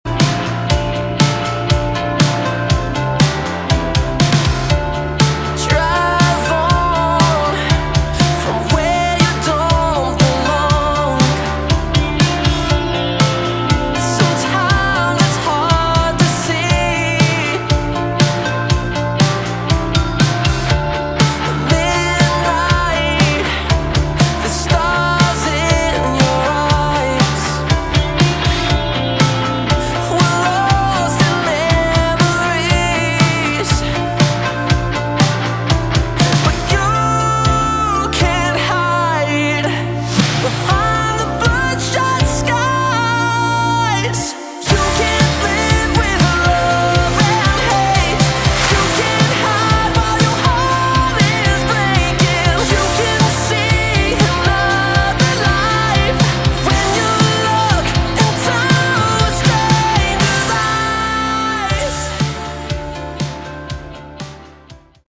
Category: Melodic Rock / AOR
guitar, vocals
keyboards, vocals
bass